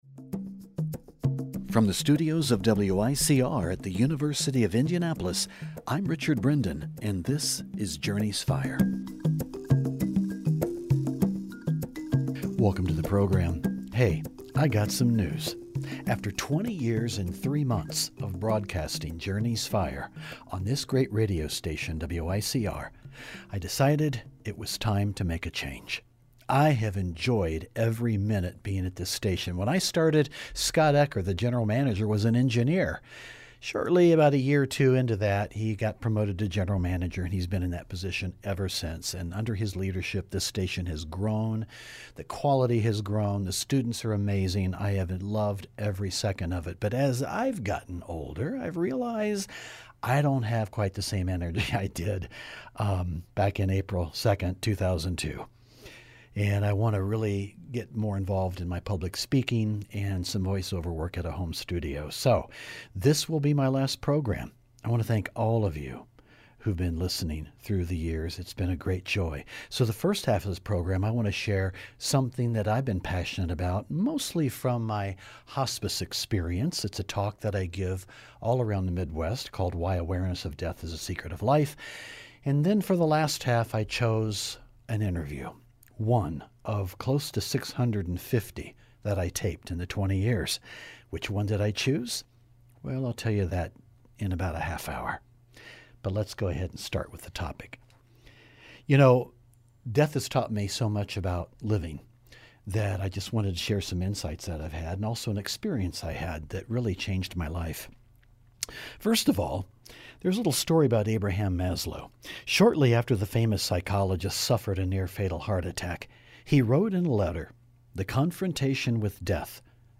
Conversations on the Art of Living and Loving